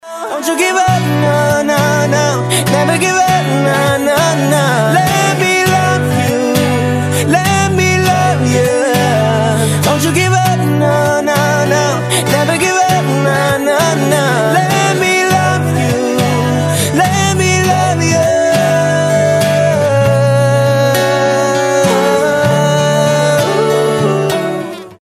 • Качество: 256, Stereo
поп
мужской вокал
Cover
романтические
Acoustic
vocal